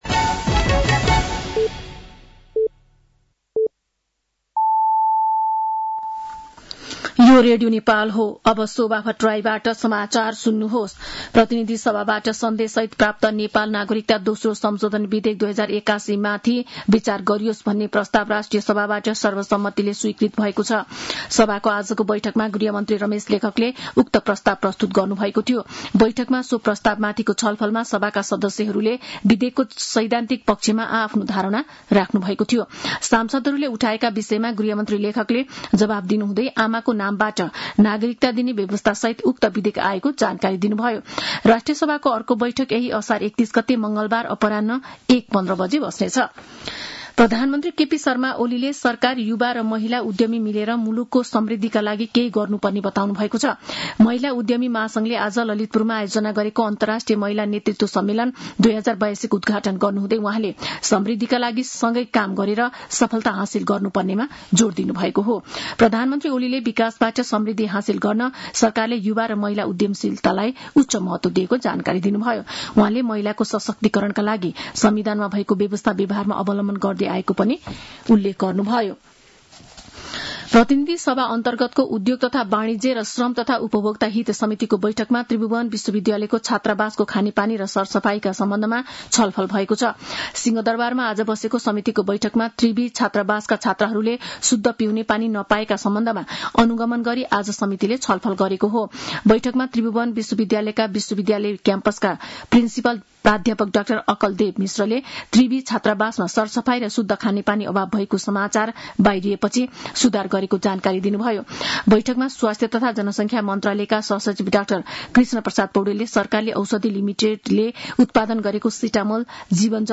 साँझ ५ बजेको नेपाली समाचार : २७ असार , २०८२
5-pm-nepali-news-3-27.mp3